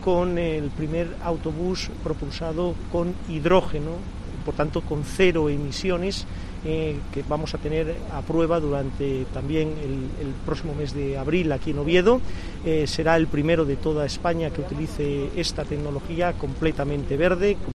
Ignacio Cuesta anuncia la puesta en circulación del primer bus impulsado por hidrógeno